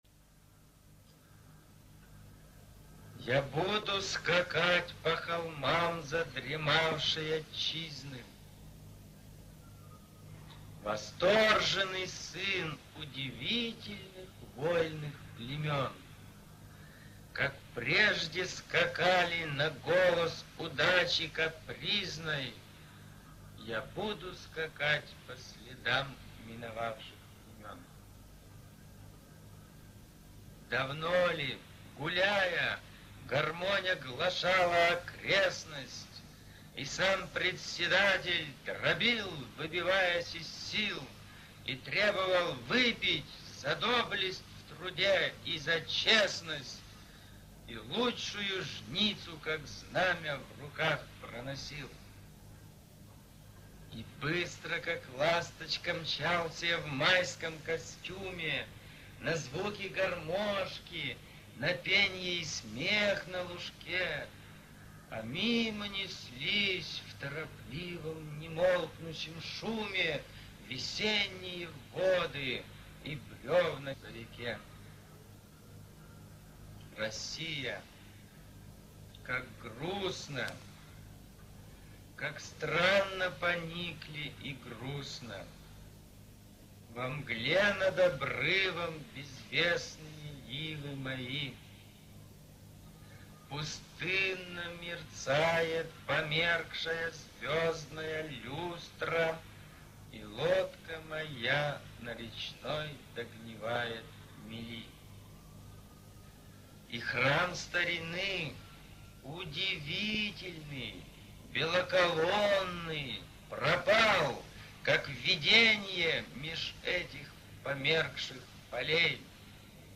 Я буду скакать по холмам задремавшей Отчизны...-в авторском исполнении